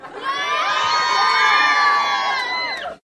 Звуки фанатов на концерте
Подростки фанаты